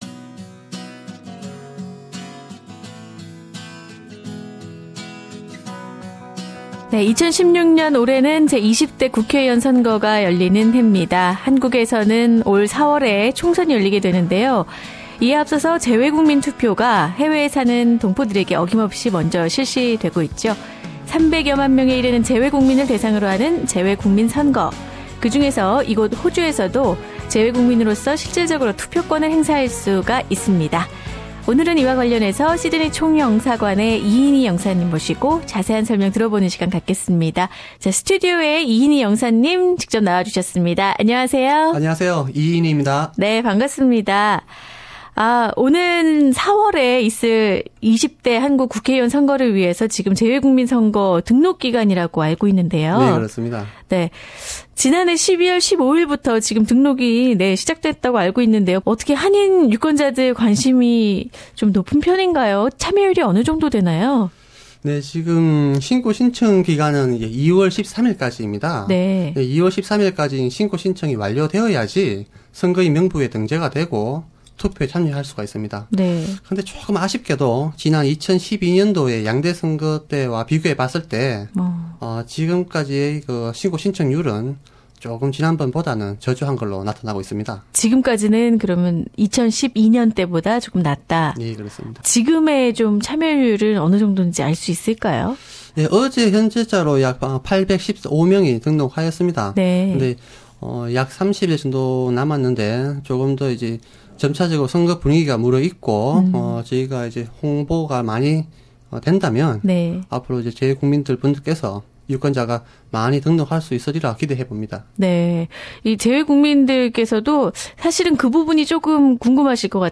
[Interview] Consul Inhee Lee in charge of overseas voters' enrolment